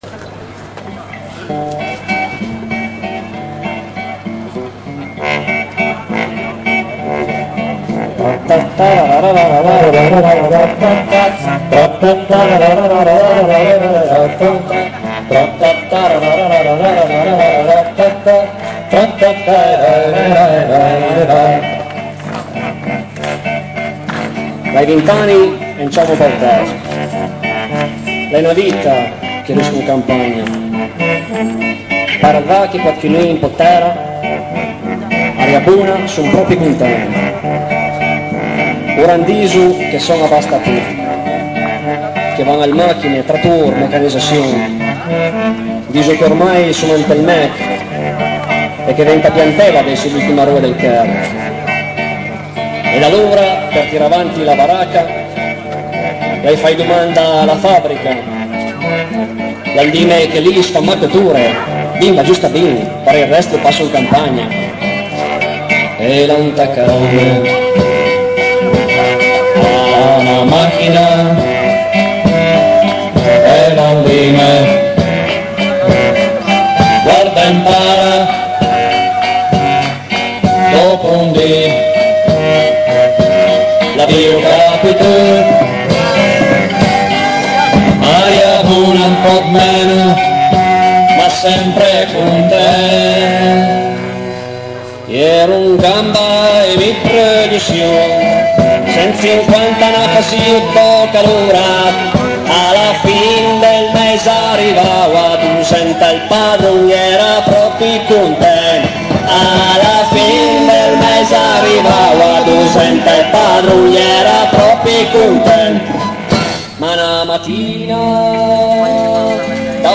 ballata in piemontese
live 1972
batterista